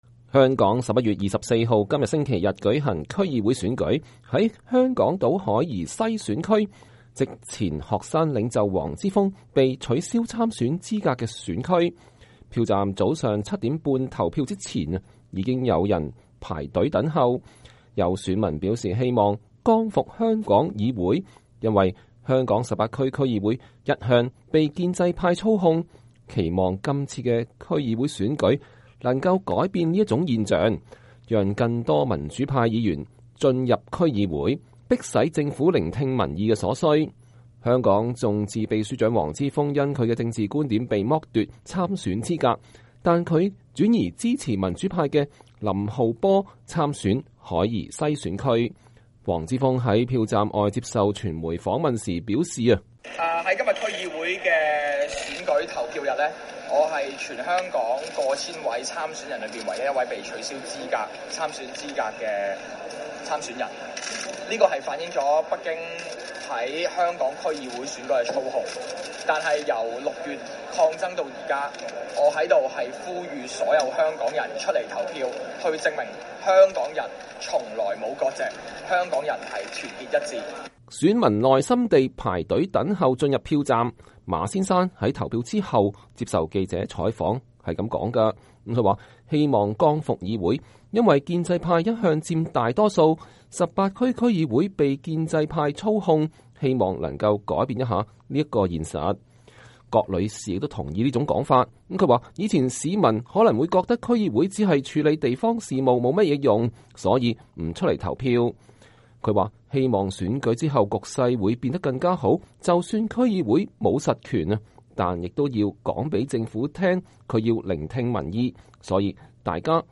黃之鋒在票站外接受傳媒訪問表示，“我是唯一一個被取消參選資格的參選人，這反映了北京在香港區議會選舉的操控。